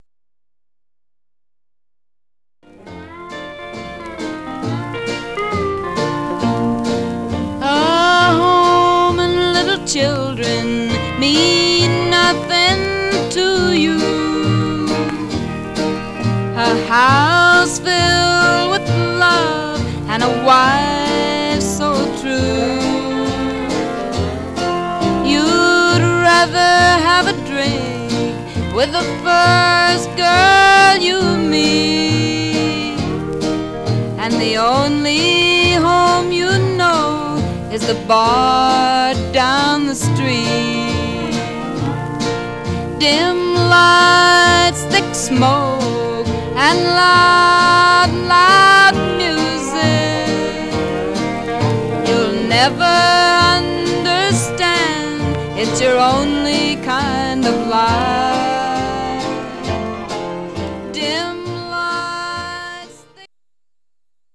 Surface noise/wear
Mono
Country